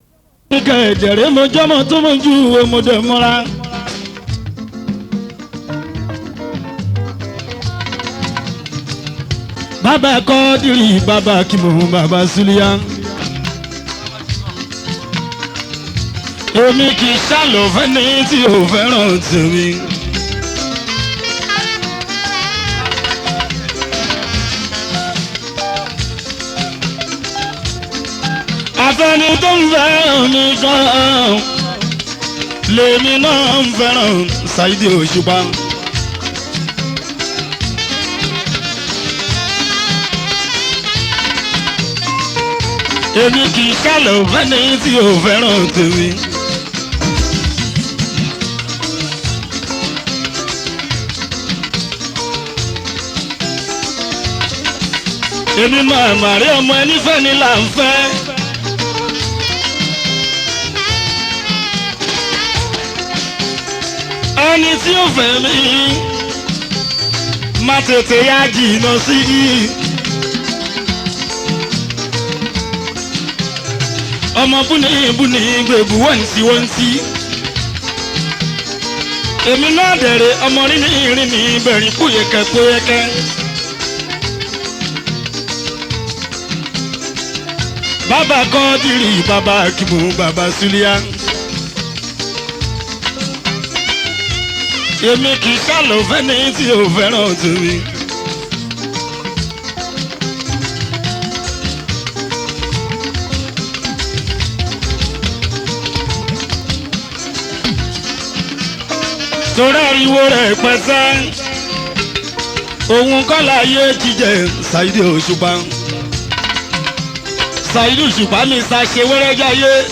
Yoruba Fuji song